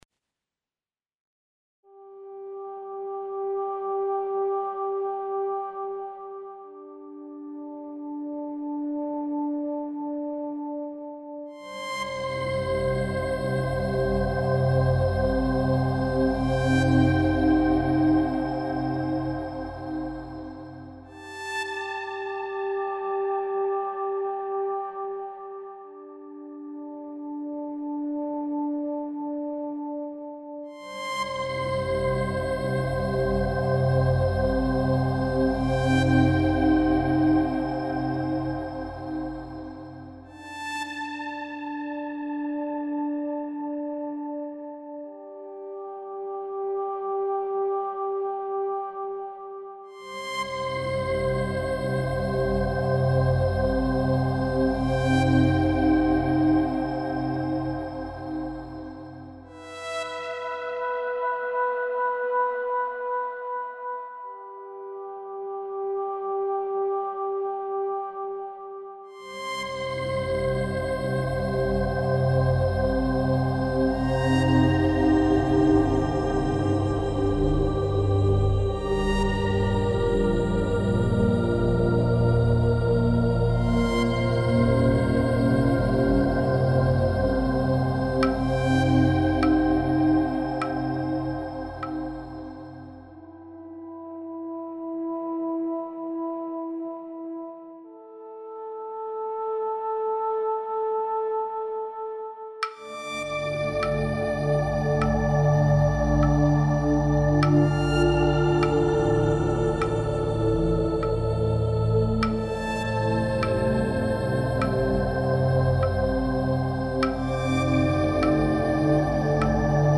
Welcome to our nature inspired world of visual soundscapes.